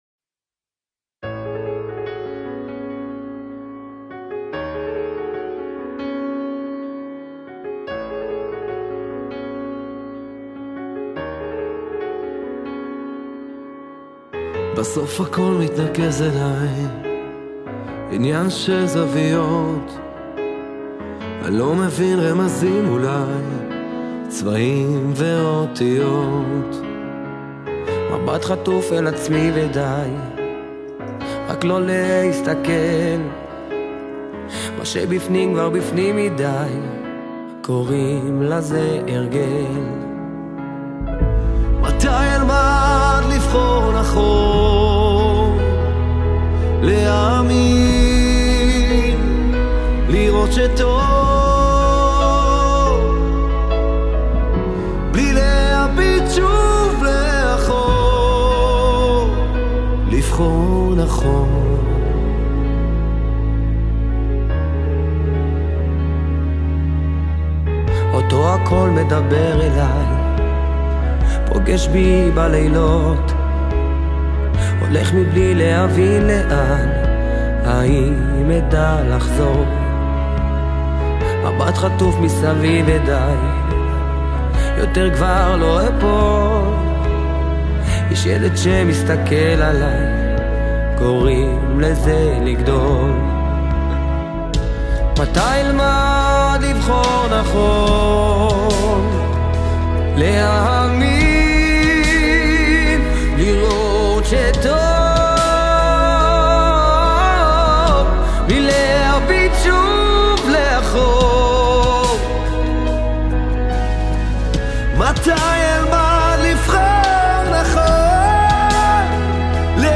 להקה לחתונה (דתית-חרדית)